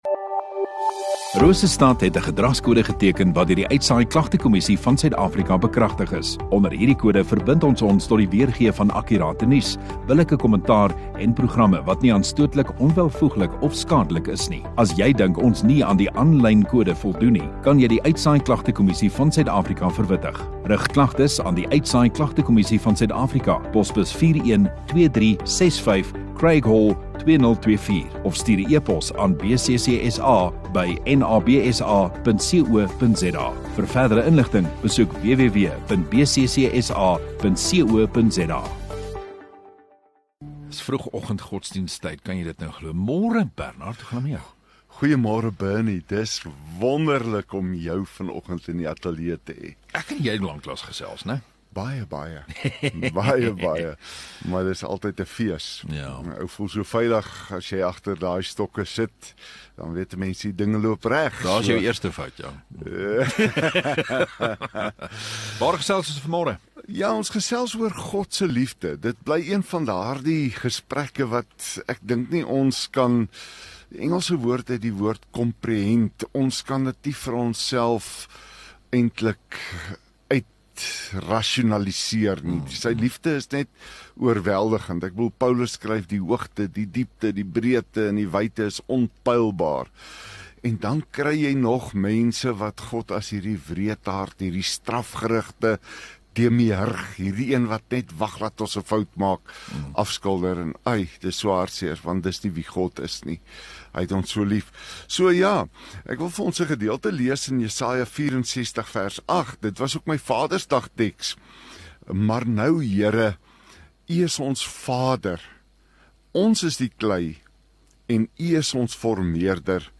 17 Jun Dinsdag Oggenddiens